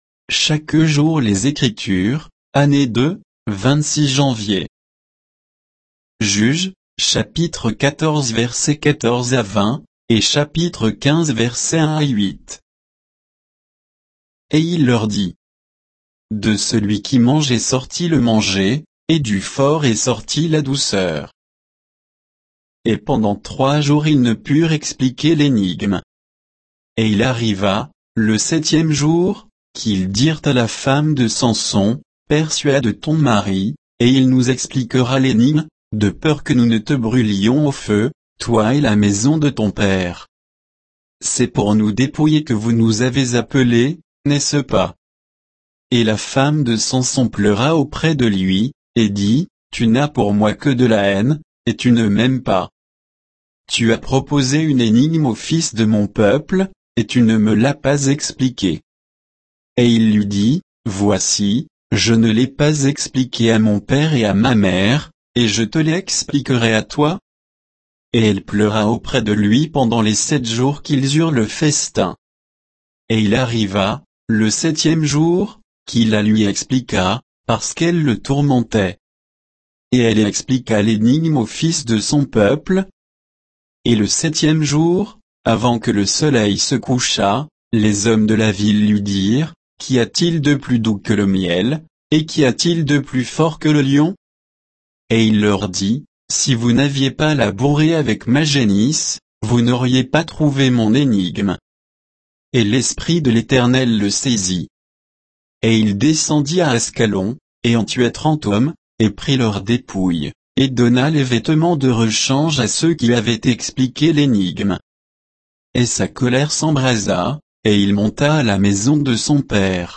Méditation quoditienne de Chaque jour les Écritures sur Juges 14, 14 à 15, 8